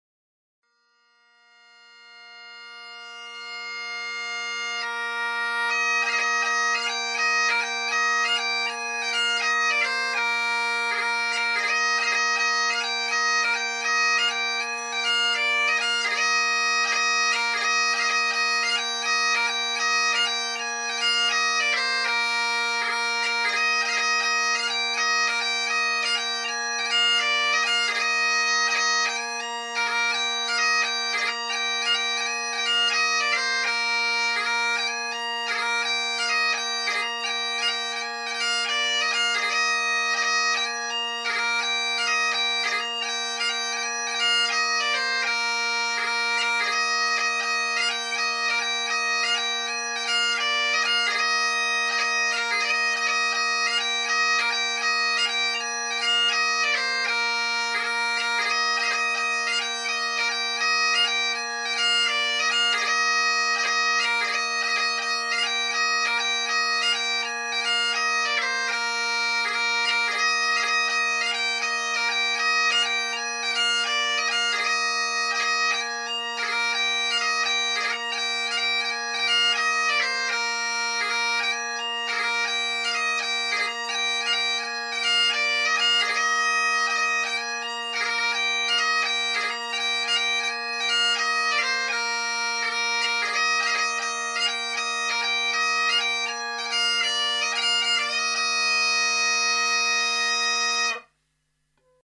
Our 'resident' musicians provide traditional music aboard the accommodation vessels and in addition there will be a number of shore events, including an opening reception and the memorable final prize-giving ceilidh.